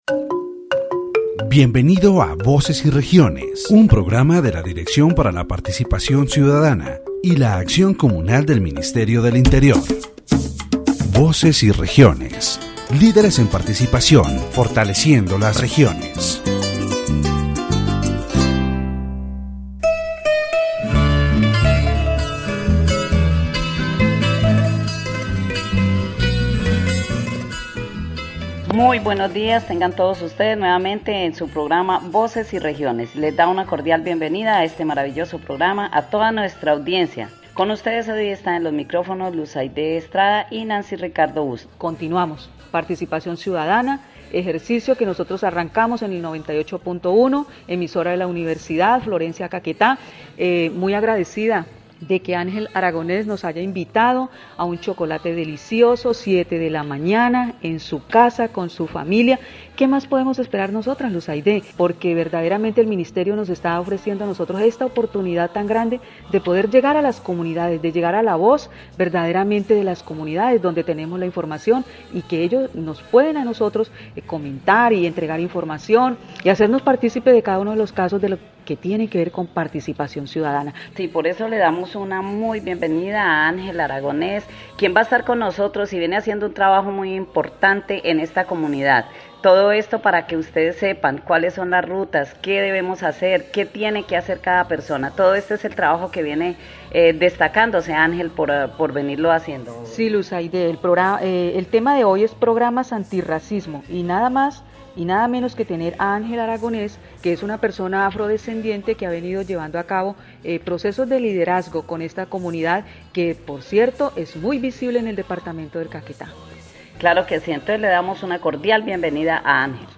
The radio program "Voces y Regiones" of the Ministry of the Interior is broadcast from Florencia, Caquetá, on station 98.1. In this episode, the issue of racism and discrimination in the department of Caquetá is addressed.